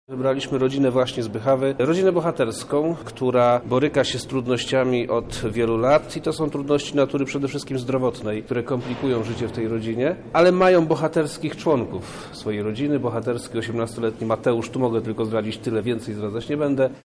W czasie spotkania wojewoda zdradził nieco o rodzinie, do której trafią zebrane produkty żywnościowe oraz lodówka: